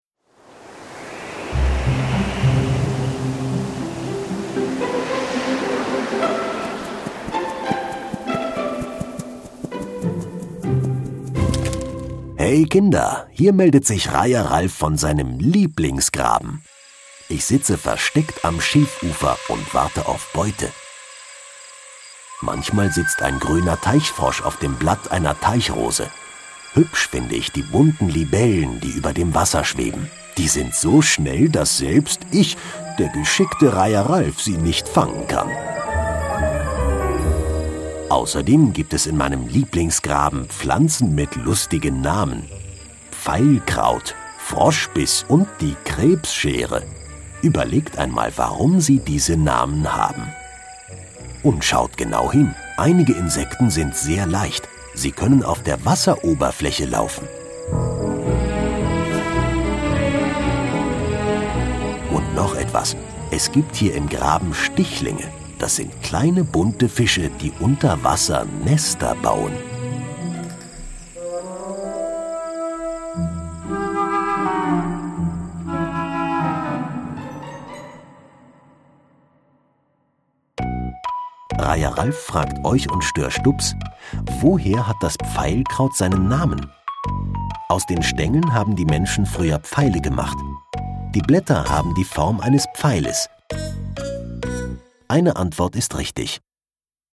Pfeilkrautgraben - Kinder-Audio-Guide Oste-Natur-Navi